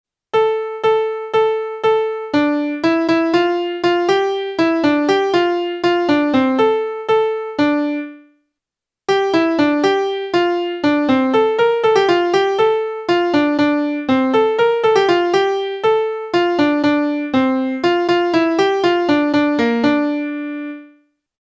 As an experiment, below the tune is transposed down a fourth so that it begins on a’, the reciting note of the dorian mode.
MirieAnalysis002TransposedDorianEndaudio.mp3